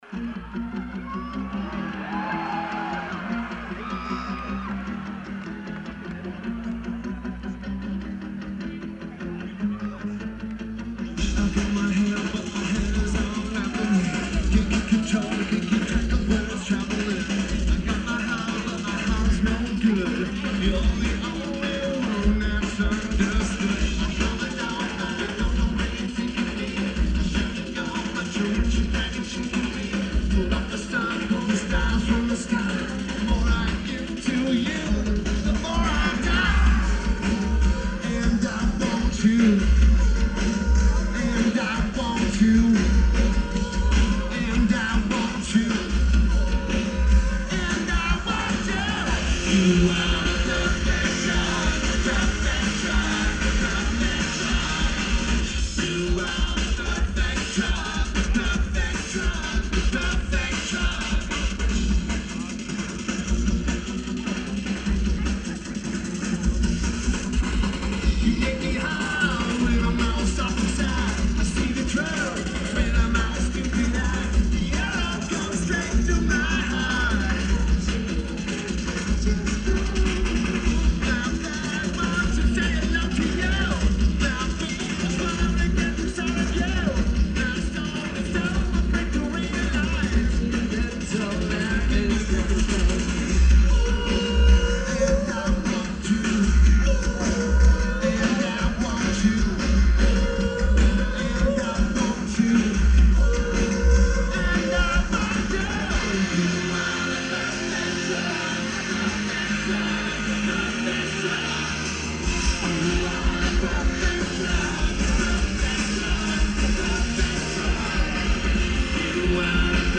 Lineage: Audio - AUD (bootlegMIC + Pixel 6)
It is a mono recording.